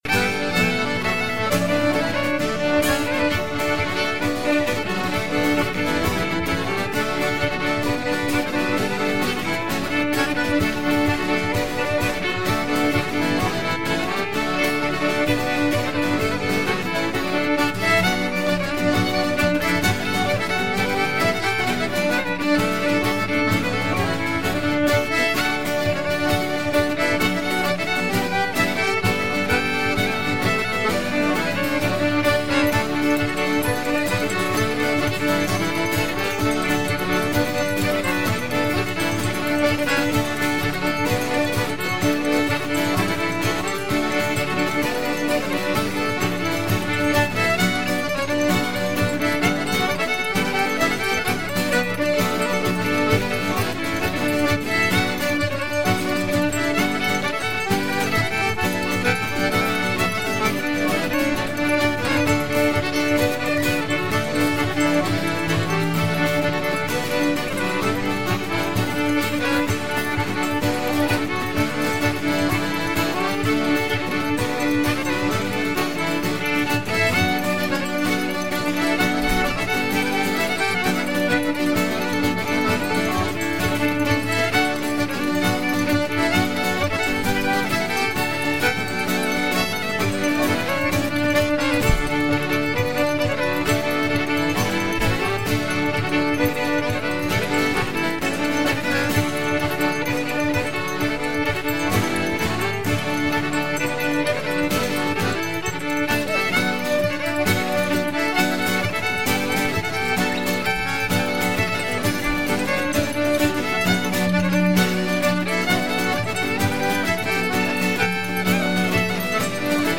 zpzpMusique Trad en Poitou
Bal aux Roches-Prémarie, salle du Clos des Roches